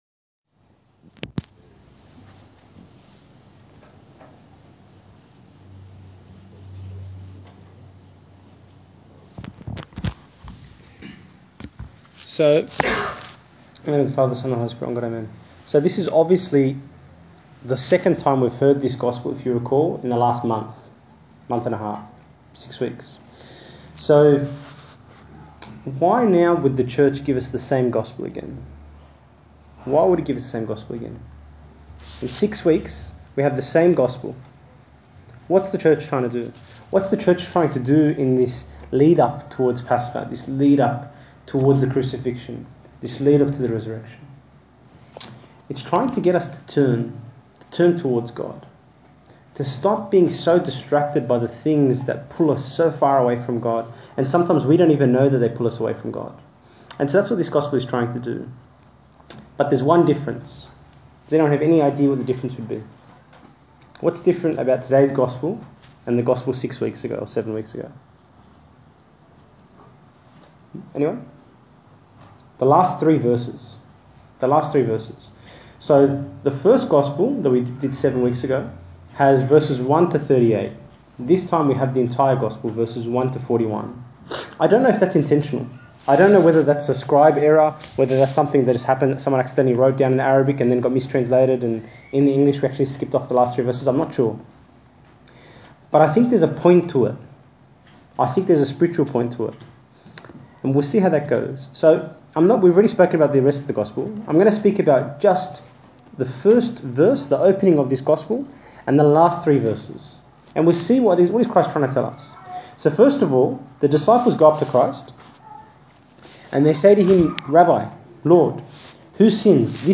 Wollongong Sunday Sermons